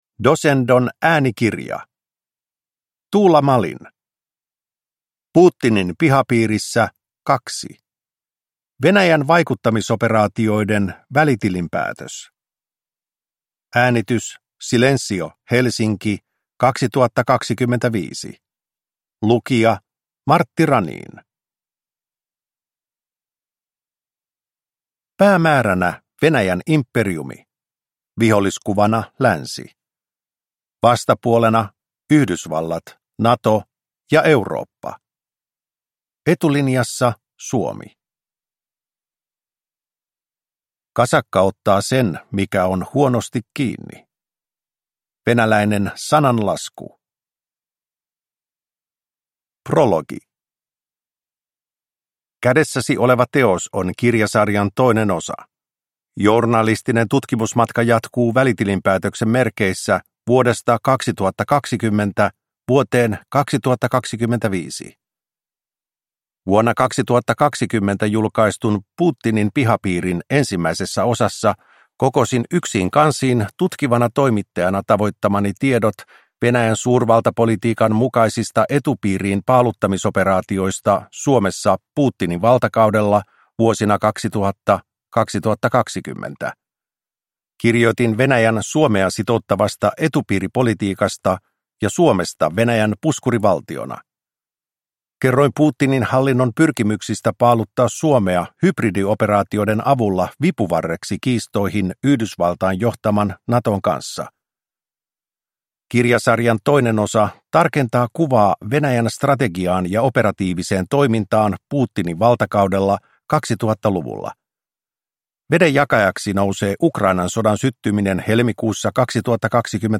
Putinin pihapiirissä II – Ljudbok